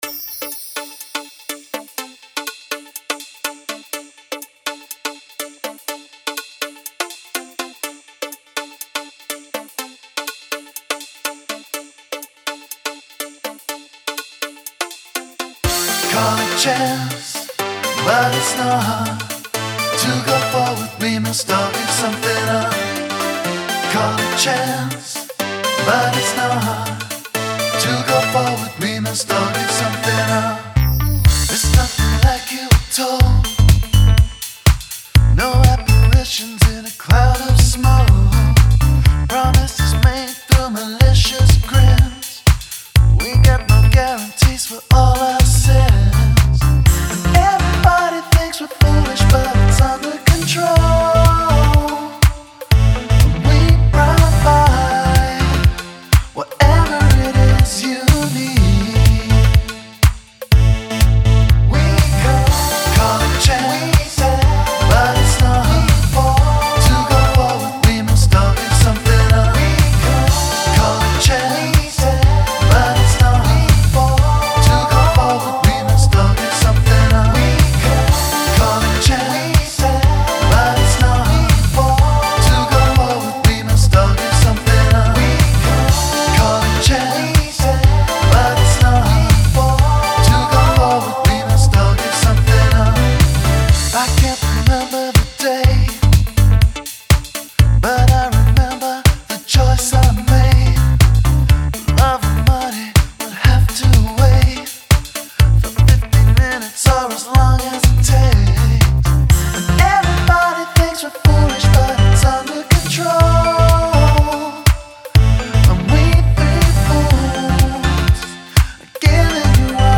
vocals
guitar
keytar, synth